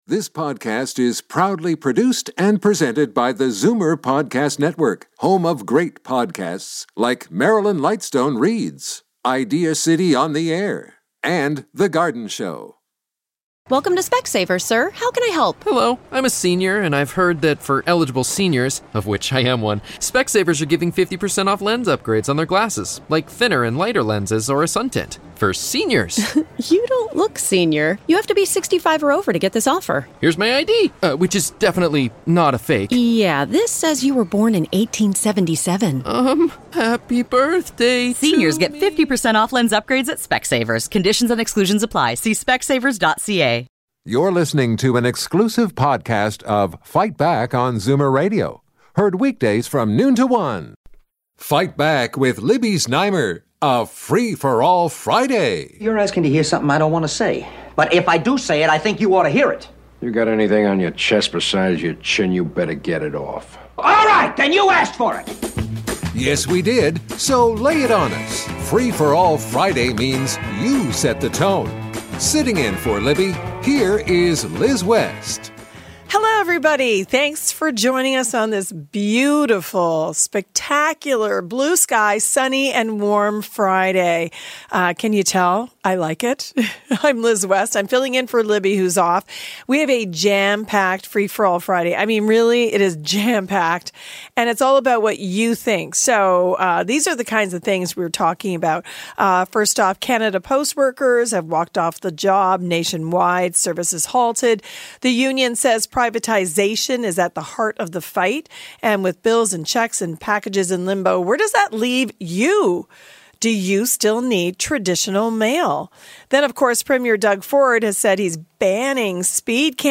Today on Free For All Friday: listeners phoned in to talk about speed cameras, their jury duty experiences, Brad Bradford entering next year's mayoral race, and more.